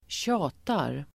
Uttal: [²tj'a:tar]